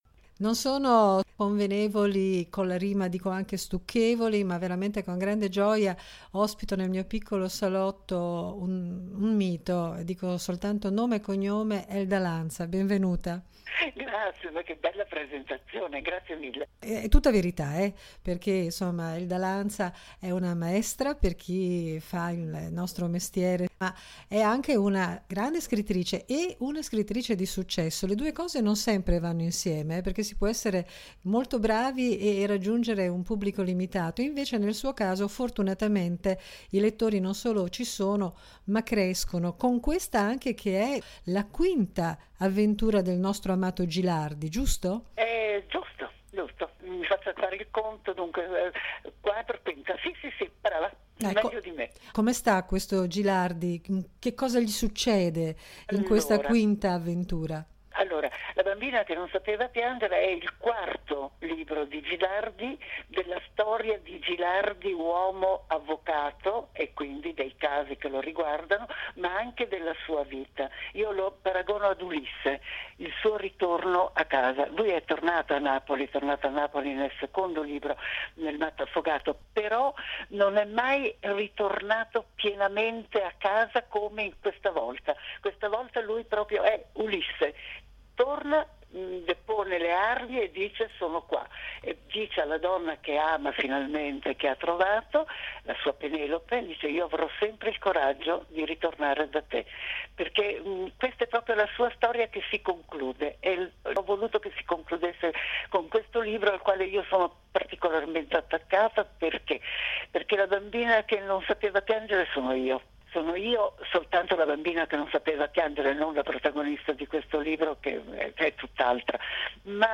L’ho incontrata qualche anno fa e ho avuto il piacere di intervistarla altre volte nella sua attuale veste di scrittrice di successo: ma con Elda Lanza si può parlare di tutto ed è un piacere ascoltare le sue risposte, sempre argute e intelligenti, pronunciate dalla sua voce meravigliosa, che il tempo non si azzarda ad appannare e che fra pochissimo potrete ascoltare voi stessi nell’intervista che ho raccolto per parlare con lei del suo nuovo romanzo.